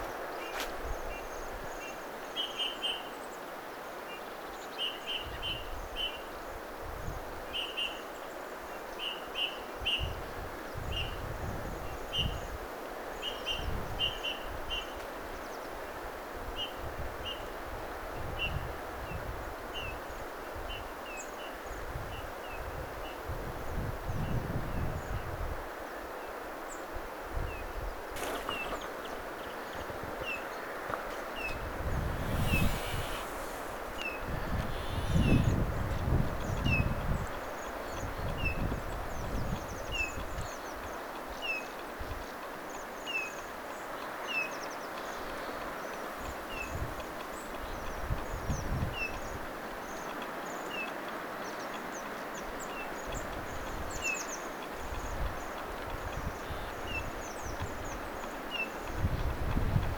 tuollaista punatulkun ääntelyä
tuollaista_punatulkkulinnun_aantelya_toinenkin_lintu_joka_aantelee_eri_tavalla.mp3